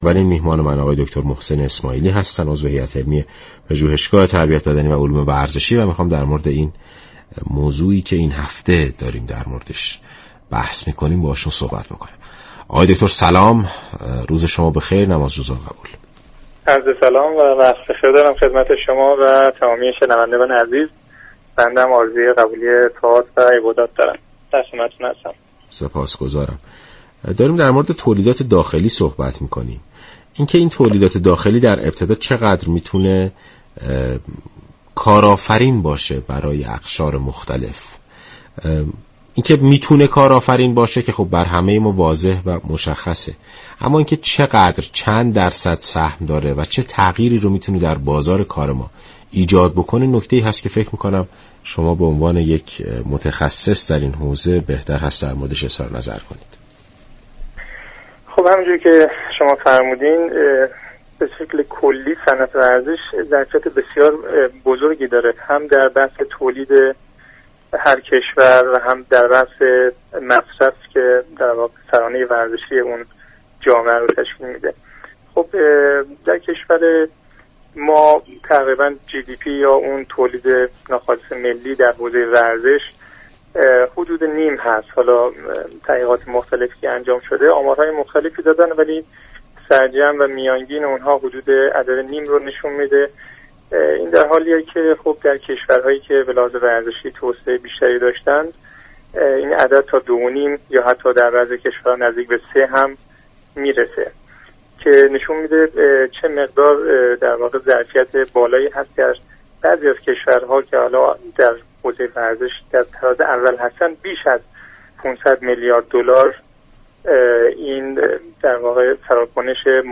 این برنامه زنده ساعت 12 هر روز بجز جمعه ها به مدت 60 دقیقه از شبكه رادیویی ورزش تقدیم شنوندگان می شود.